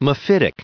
1900_mephitic.ogg